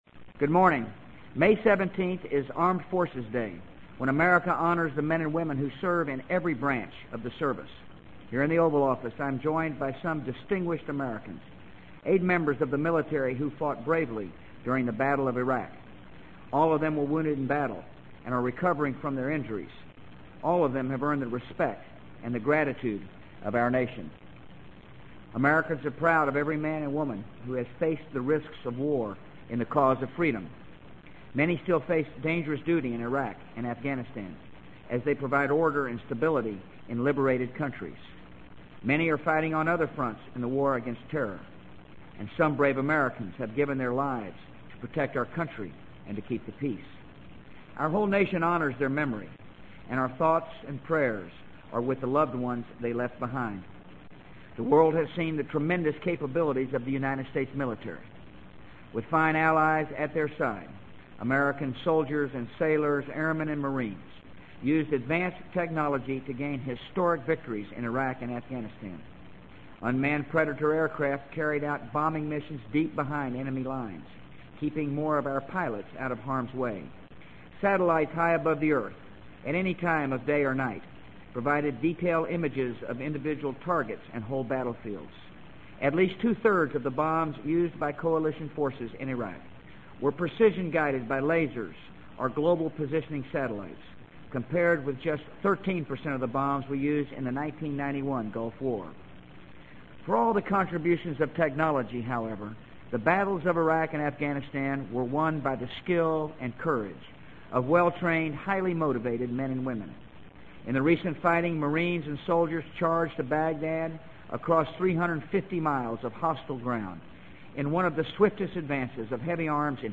【美国总统George W. Bush电台演讲】2003-05-17 听力文件下载—在线英语听力室